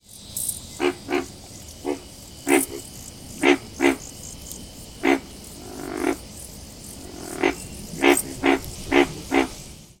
Pig Frog’s Call
Call: The call of the Pig Frog is a low grunting sound, giving the frog its common name.